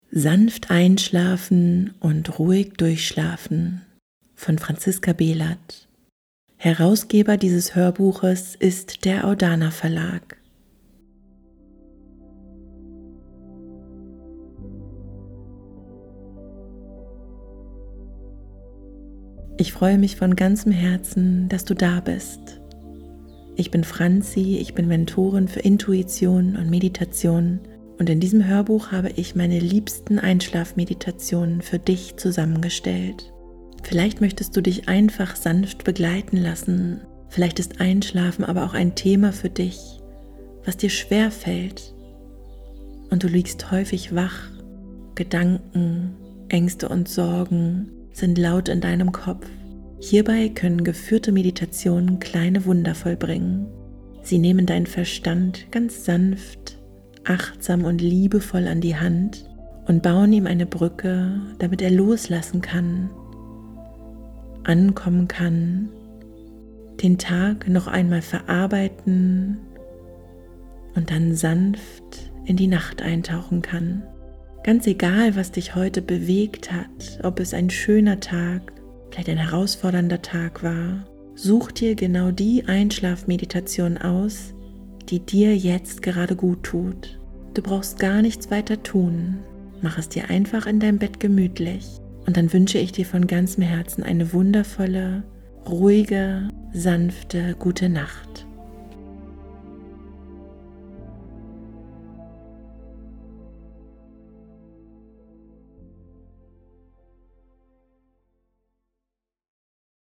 Dieses Album begleitet dich sanft durch genau diesen Übergang. Die geführten Einschlafmeditationen laden dich ein, tiefer zu atmen, weicher zu werden und dich Schritt für Schritt aus Gedanken, Anspannung und innerer Unruhe herausführen zu lassen.